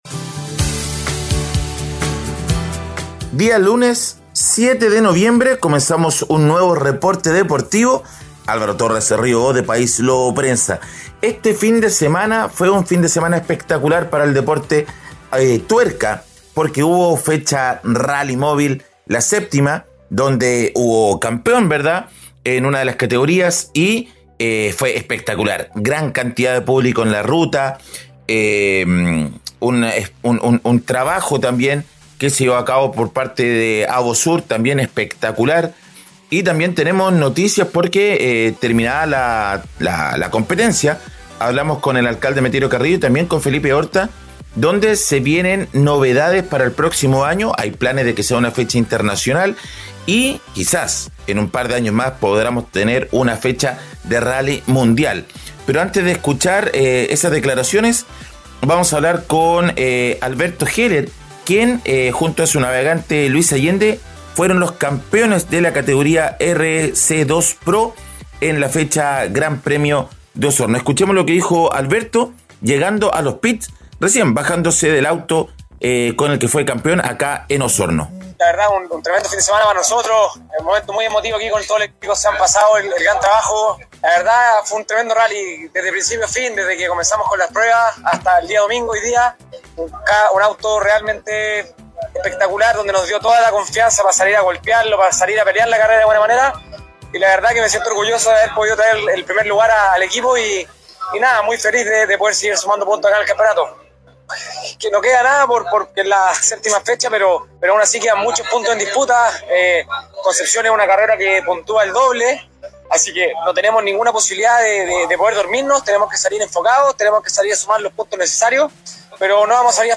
Reporte Deportivo 🎙 Podcast 07 de noviembre de 2022